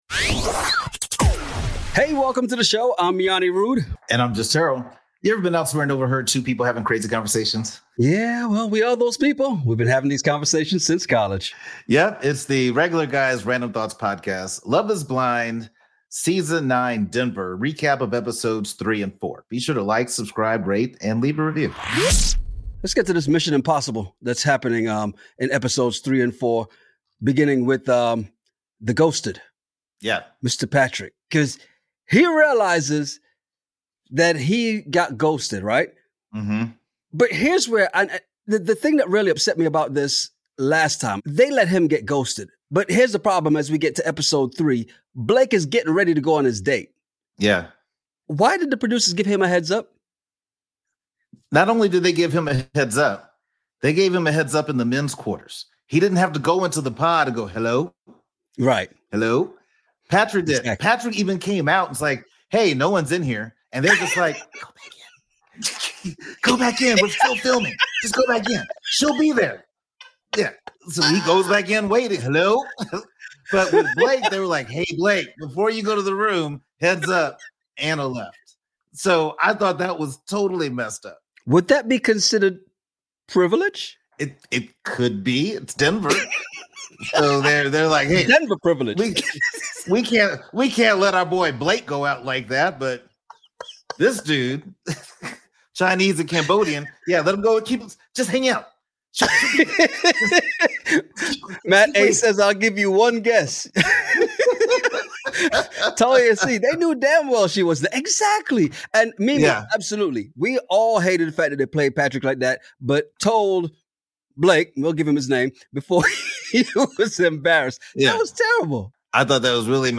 A love triangle is completely dissolved and causes a love quadrangle to become a triangle. Ever been somewhere and overheard two guys having a crazy conversation over random topics?